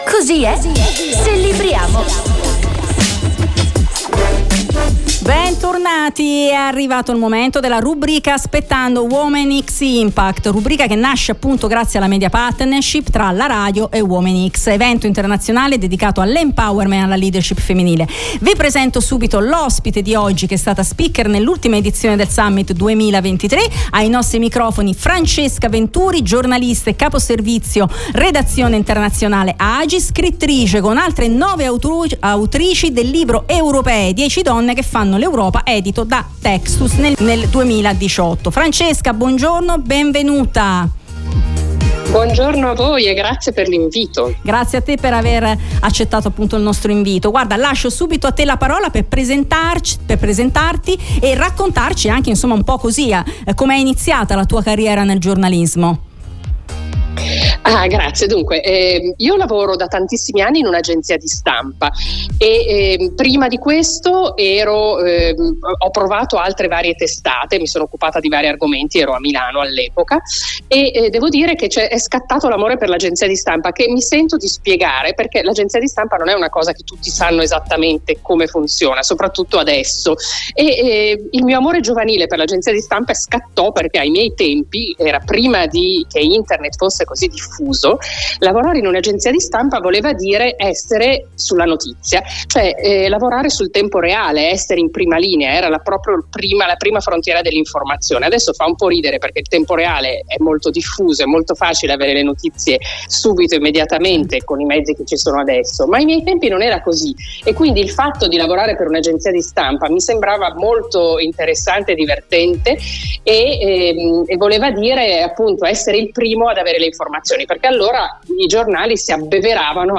Donne e giornalismo: l'intervista